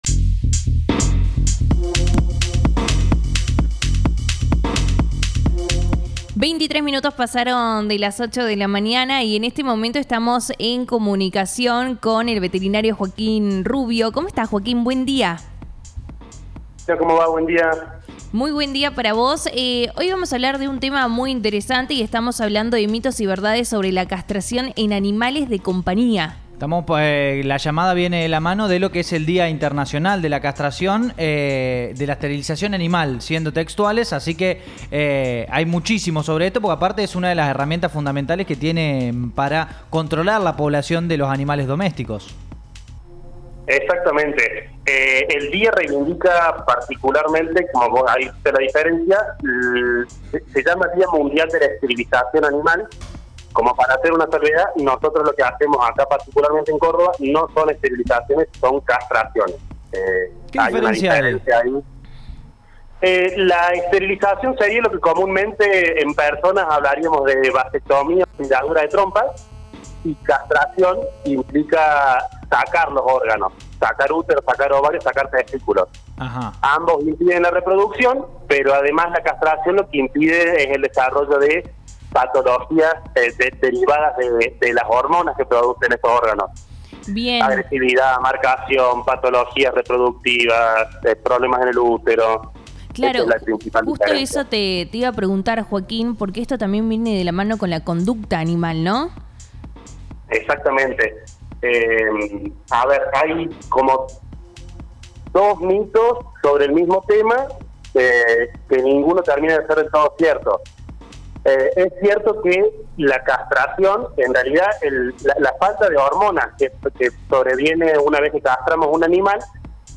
La castración temprana y planificada previene problemas de conducta y agresividad en caninos y felinos, a la vez que previene el desarrollo de tumores y patologías reproductivas, prolongando la expectativa de vida de los animales. Escuchá la nota al veterinario